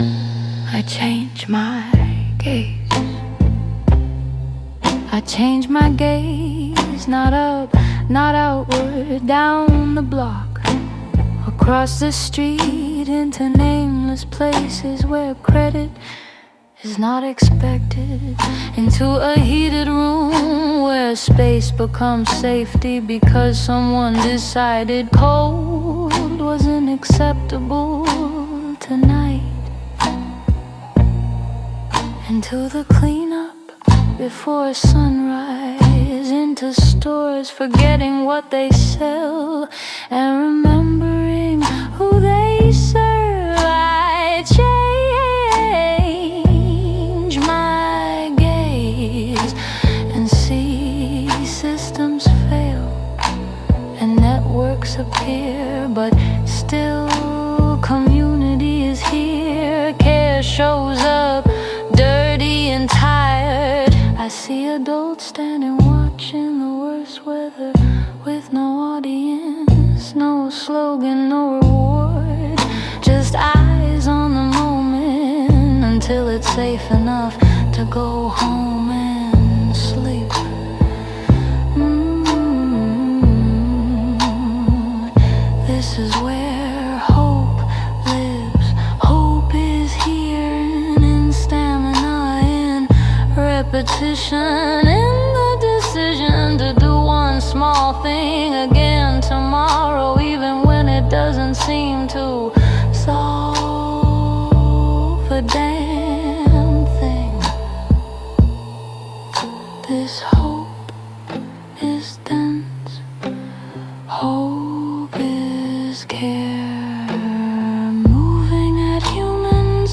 The soundscape and visuals were created with AI.